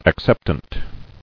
[ac·cep·tant]